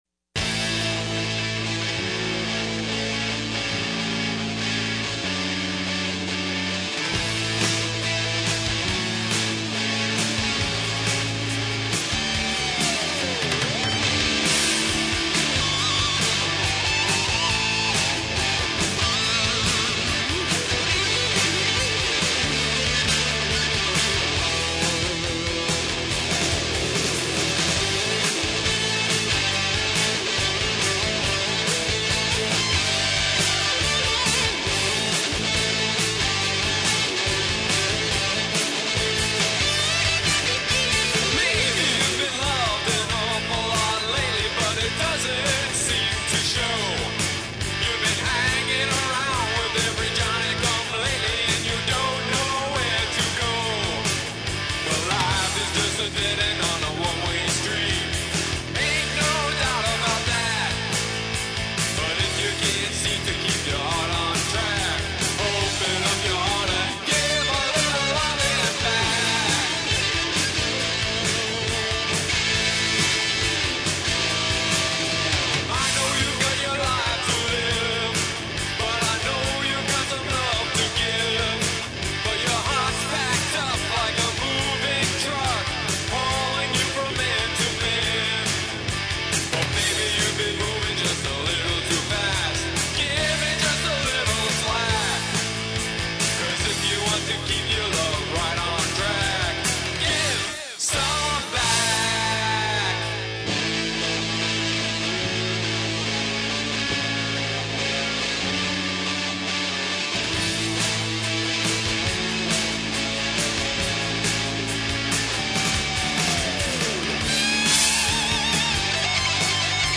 Drums
Lead Guitar
Bass and Rhythm Guitars
Lead Vocals
Background Vocals